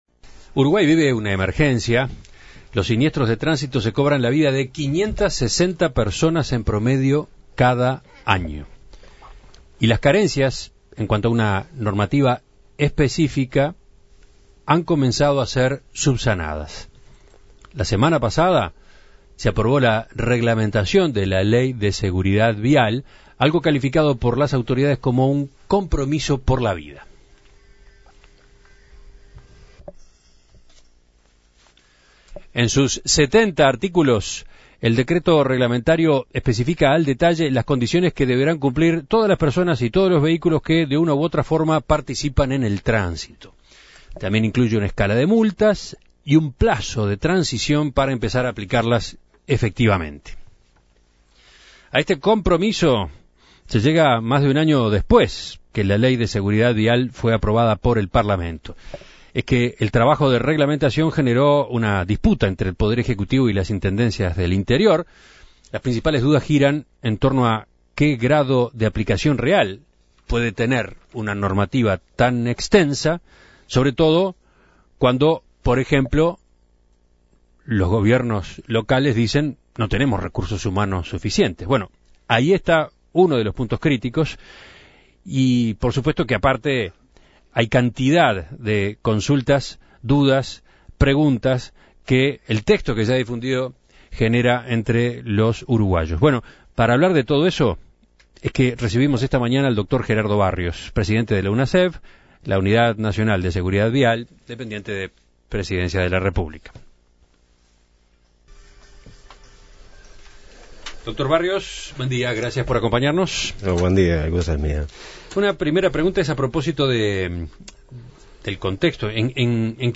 Gerardo Barrios, presidente de la Unidad Nacional de Seguridad Vial (Unasev) dijo a En Perspectiva que a nivel internacional Uruguay siempre ha estado atrasado en materia de seguridad vial.